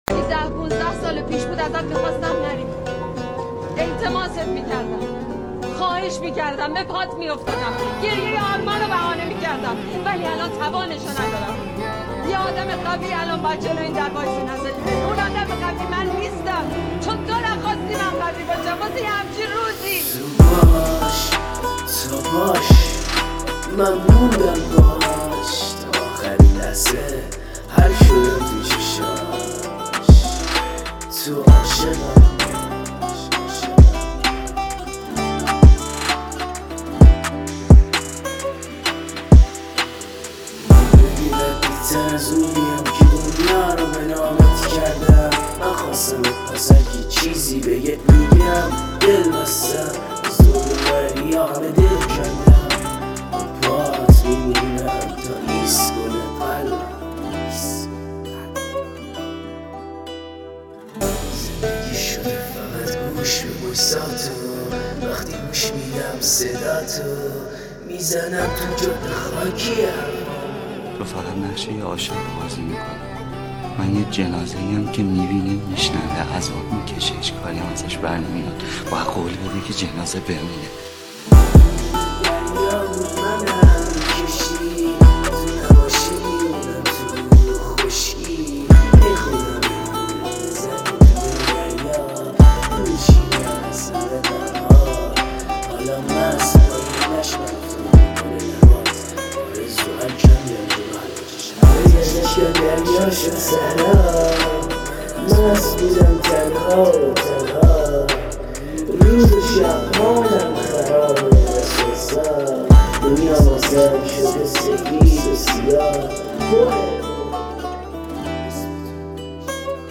موزیک غمگین